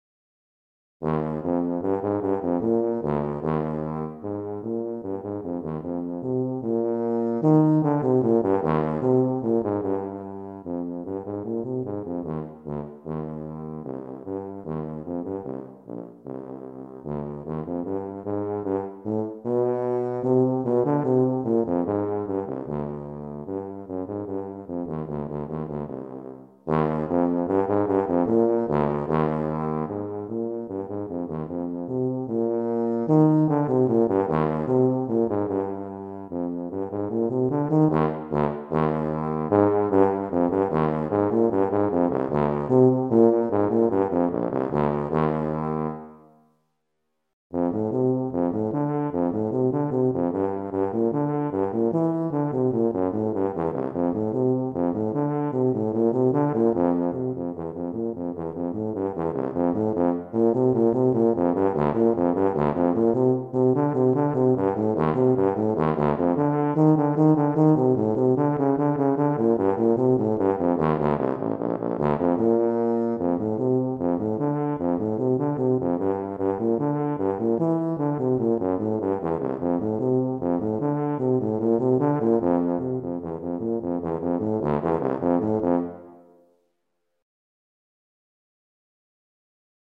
Voicing: Tuba Methods/Studies/Etudes